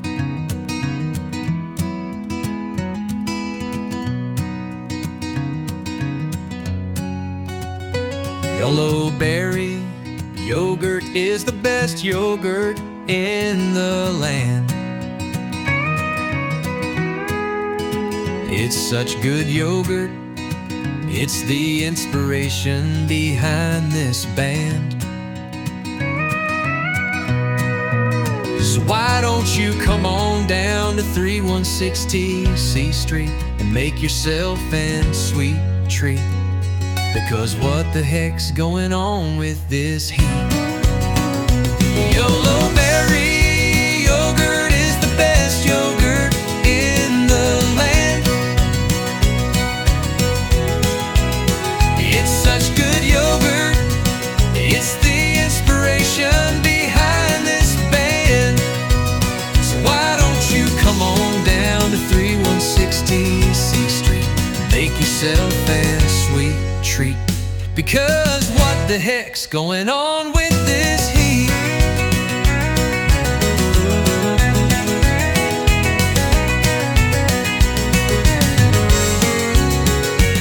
Country Jingle